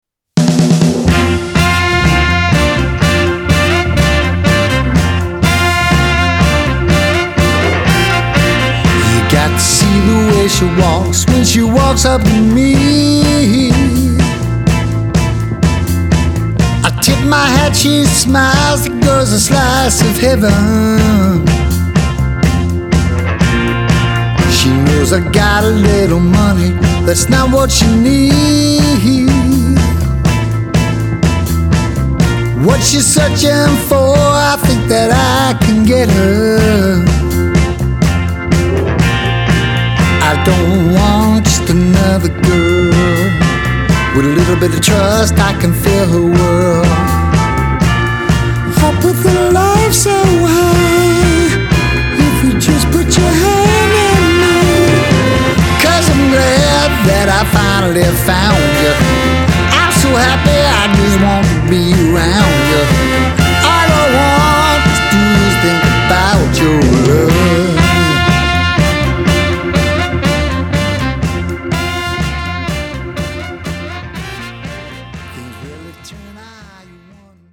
bass, bells, drums, vocals
organ
guitar
saxophone
trumpet